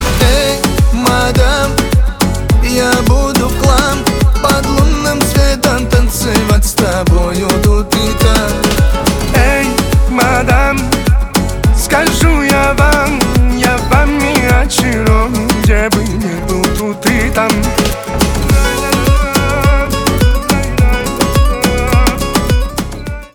поп
романтические , гитара , барабаны